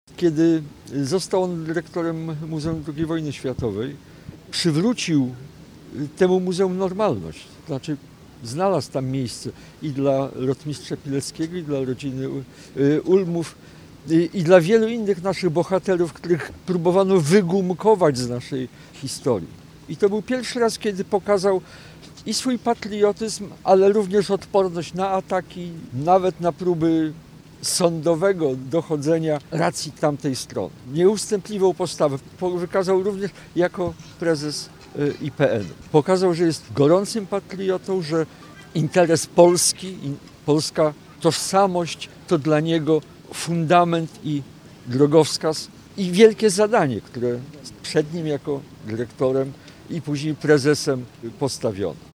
Poseł Jacek Świat wskazywał, że poznał się z Karolem Nawrockim służbowo, jako członek Komisji Kultury.